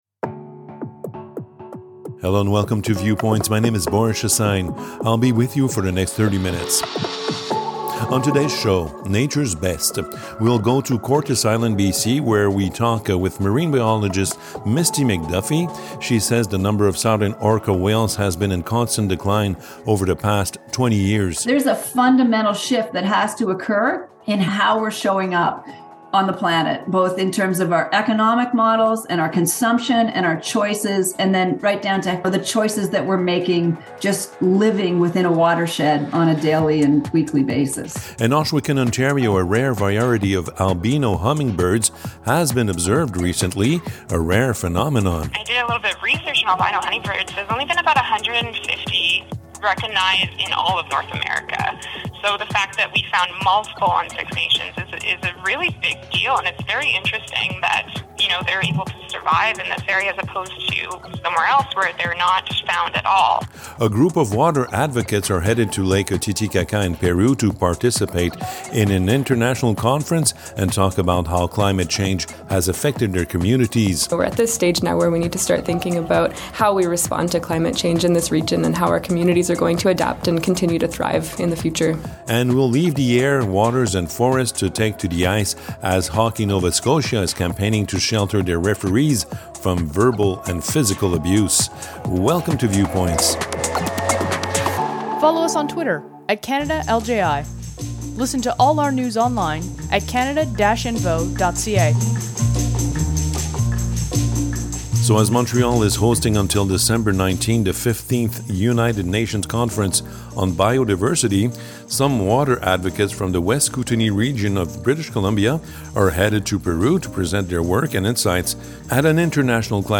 Thanks to all the artists who grace us with their music. Viewpoints is produced by the Community Radio Fund of Canada.